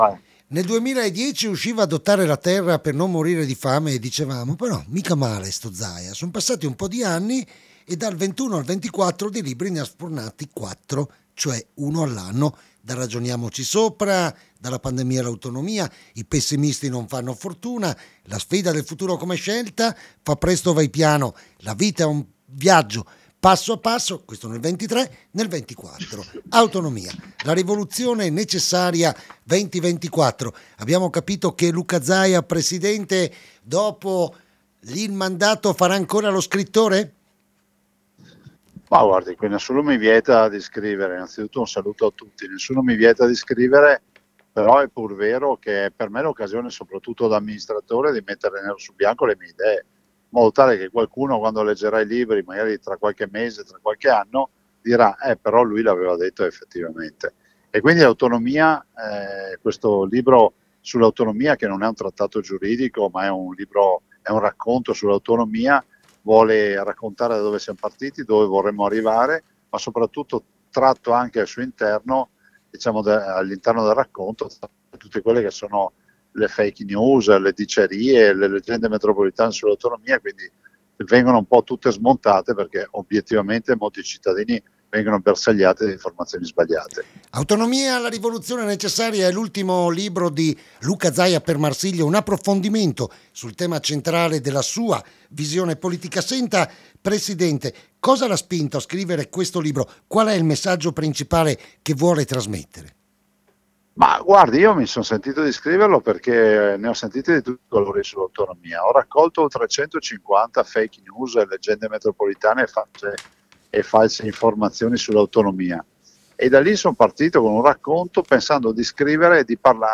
Pomeriggio alla Radio con il presidente del Veneto Luca Zaia. Occasione per presentare il suo ultimo libro Autonomia.
l’intervista